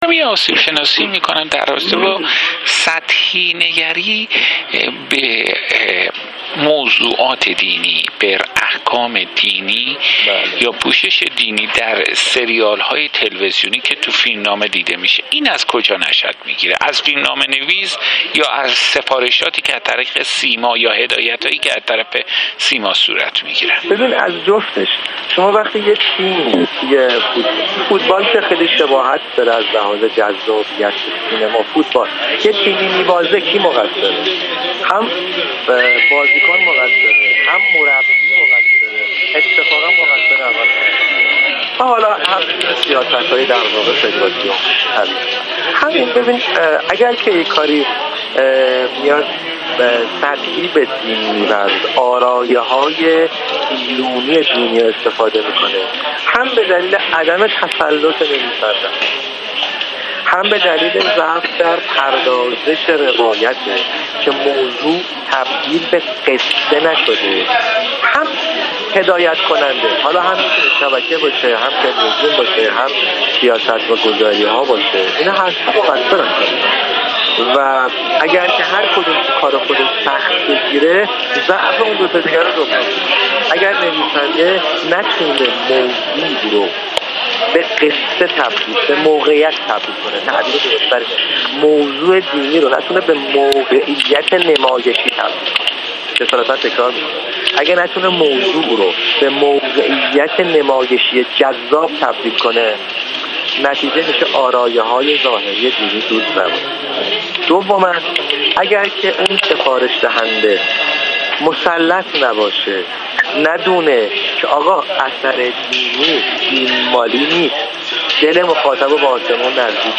در گفت‌وگو با خبرگزاری حوزه